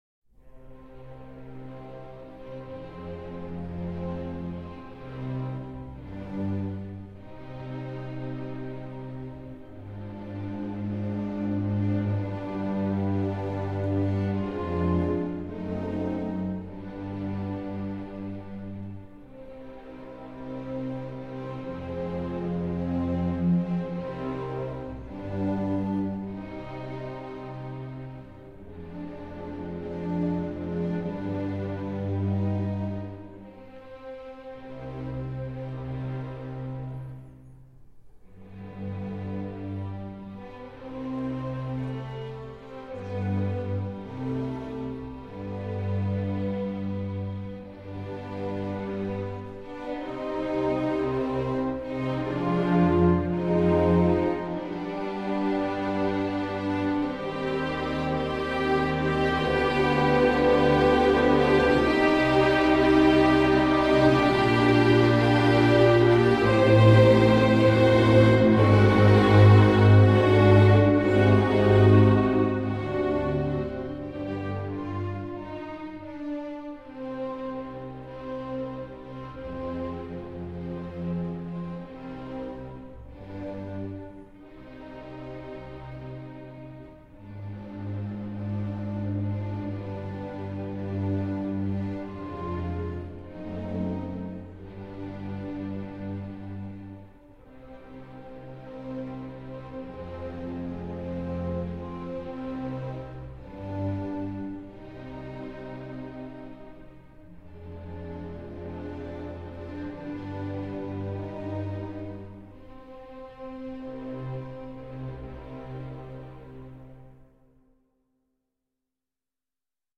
9. Гнетущая тоска